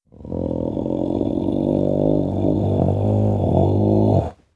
Monster Roars
15. Haunting Growl.wav